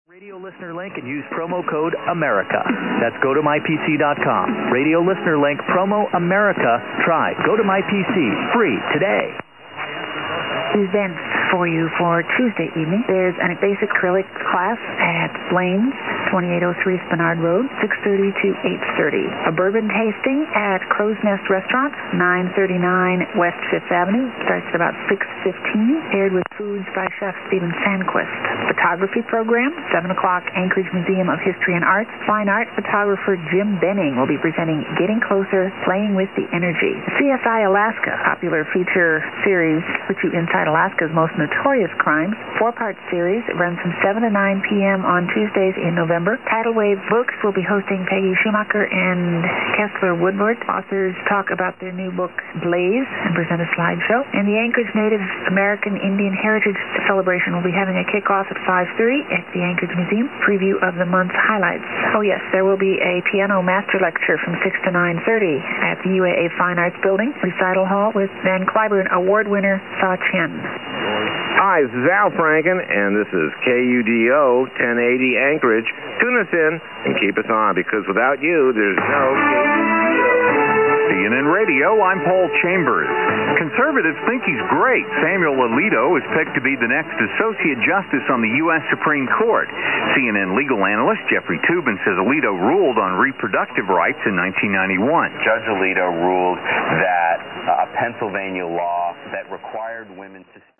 The sampling rate is not so crucial as DX recordings are already noisy. And since the original is mono, there's no sense to record in stereo.
I normally record the station ID, sometimes including other announcements and some music, with a short fade-in and fade-out.